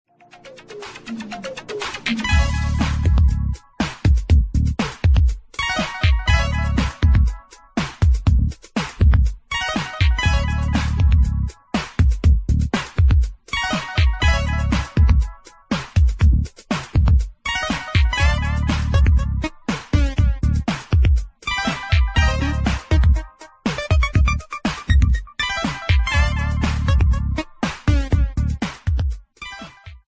Playfull discoish house tracks
Warm music from a cold territory.
Electro